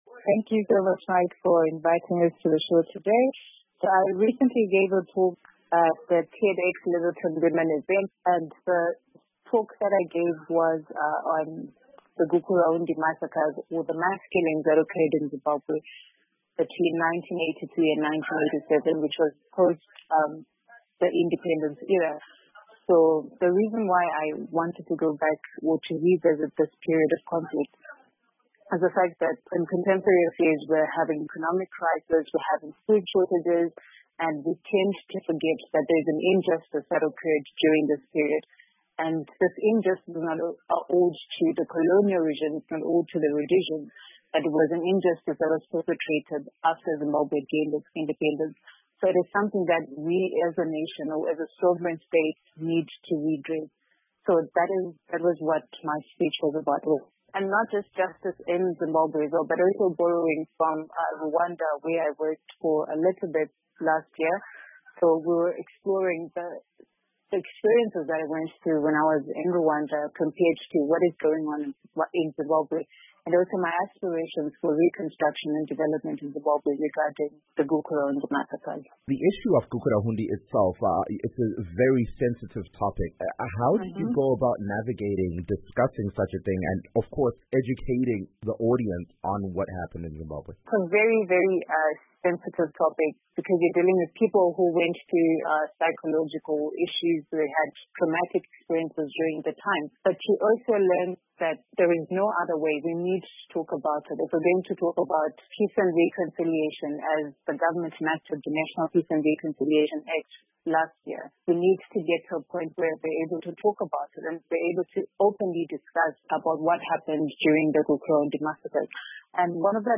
South Africa based Zimbabwean delivers Tedtalk